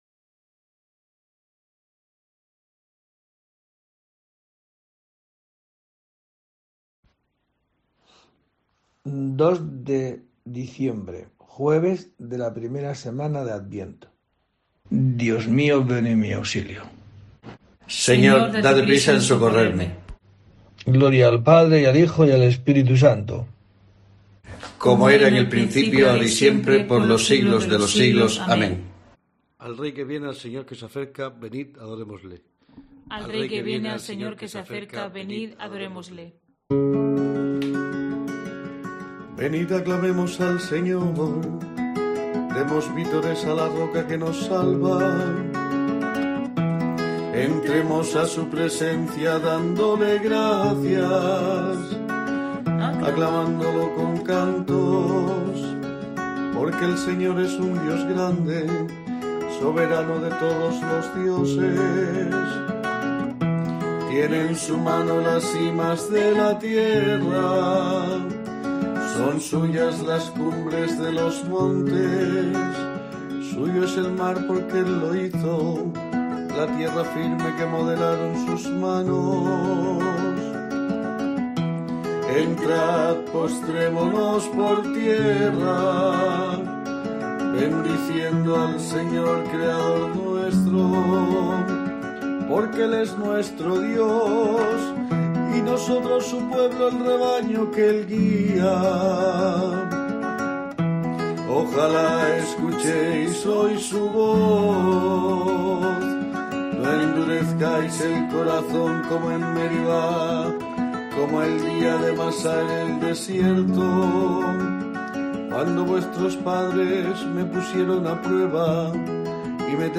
02 de diciembre: COPE te trae el rezo diario de los Laudes para acompañarte